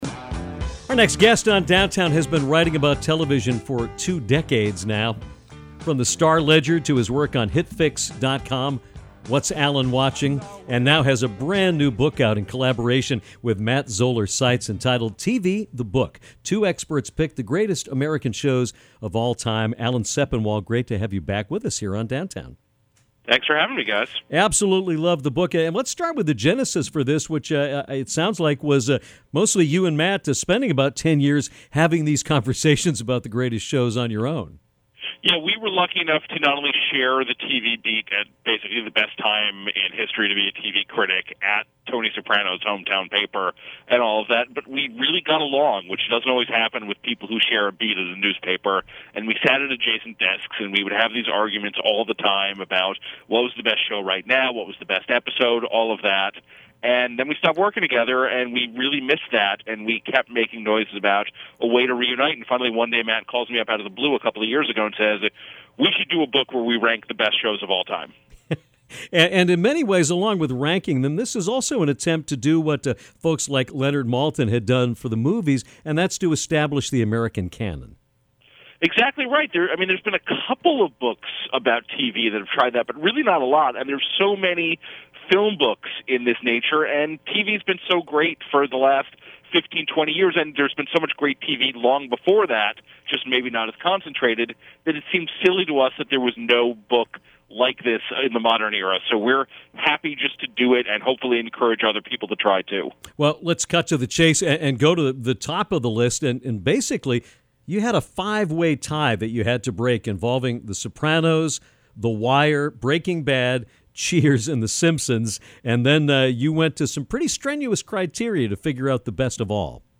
One of America’s best television critics, Alan Sepinwall, joined the show on Thursday to discuss his new book “TV (The Book)”. He describes the rubric he and partner Matt Zoller Seitz used to break an initial five-way tie for the best show in American television history.